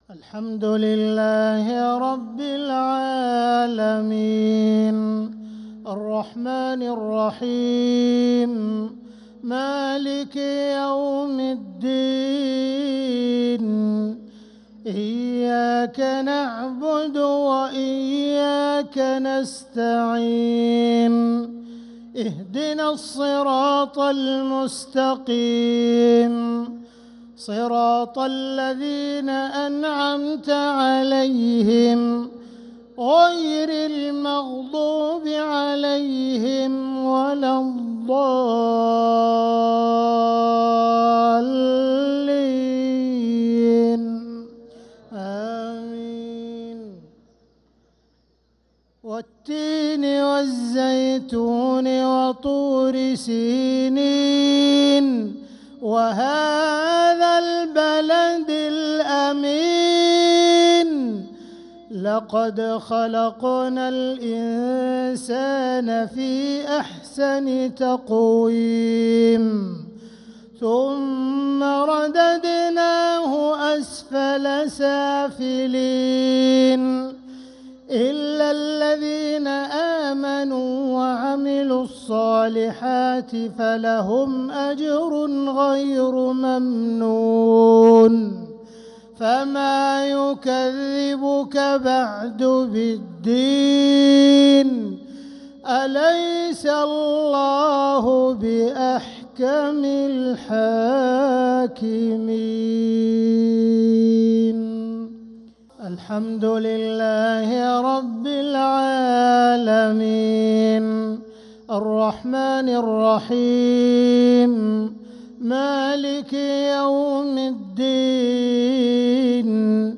مغرب الإثنين 3-9-1446هـ سورتي التين و قريش كاملة | Maghrib prayer Surat at-Tin & al-Quraish 3-3-2025 > 1446 🕋 > الفروض - تلاوات الحرمين